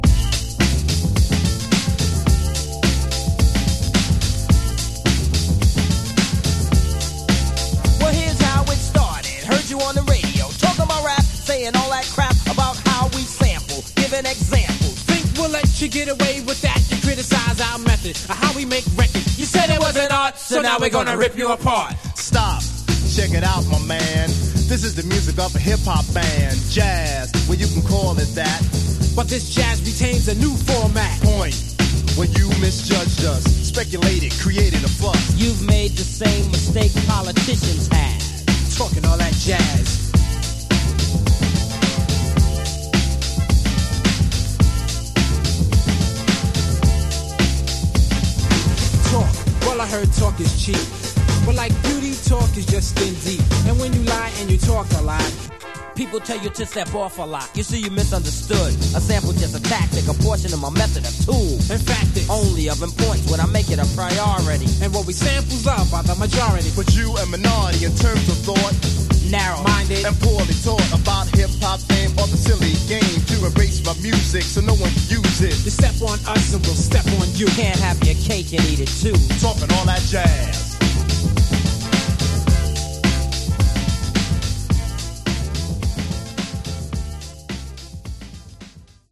Genre: Rap